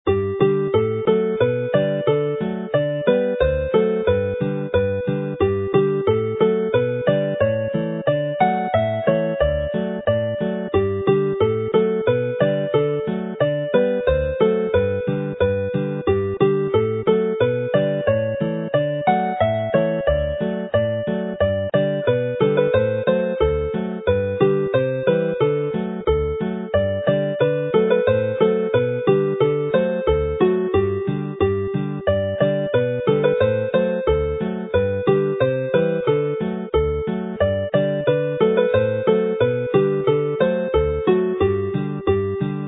Alawon Cymreig - Set Joio / Having fun - Welsh folk tunes to play
Hen alaw ddawns draddodiadol yw'r Cwac Cymreig.